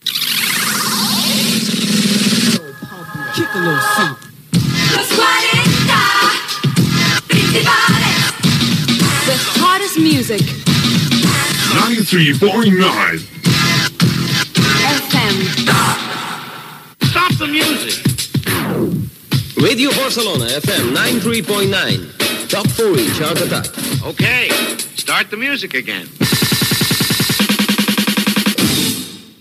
Indicatiu del programa en anglès
FM